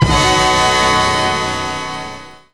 JAZZ STAB 2.wav